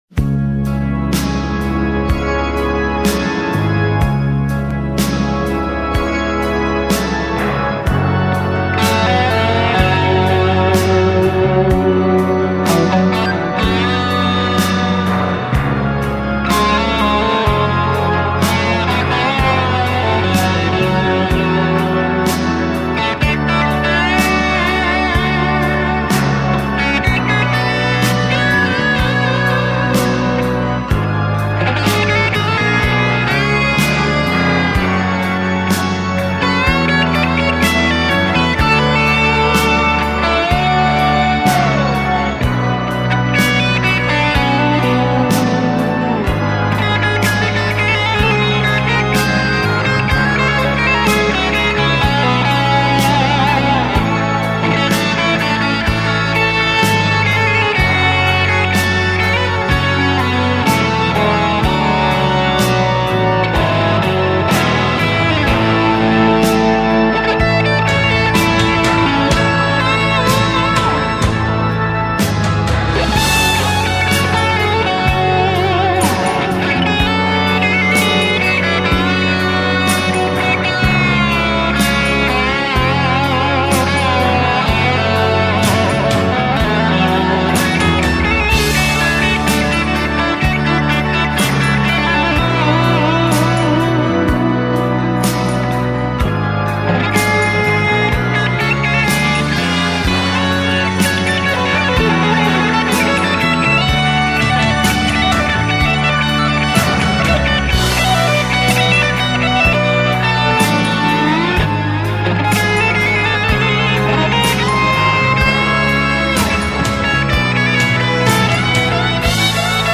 Та, которую ищу, такая "тягучая весчь".